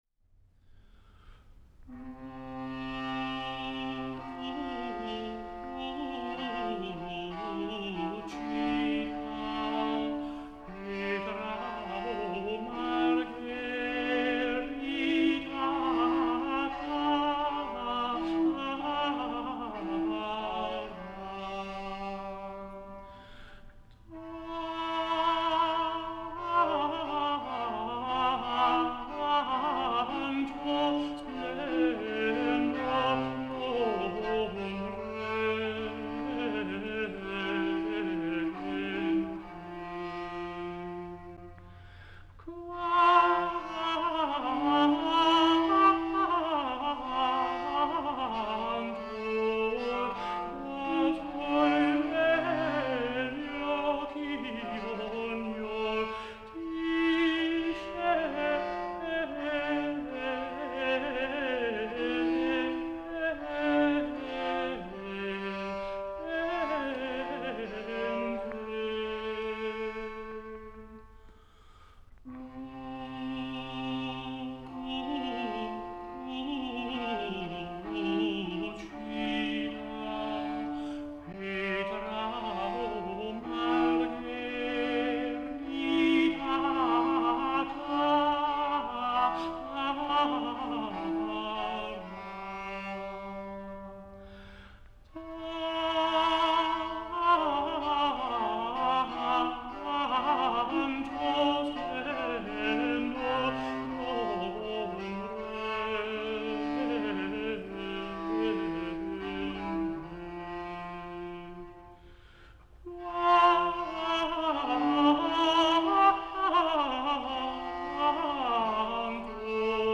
Категория: Музыка национальных традиций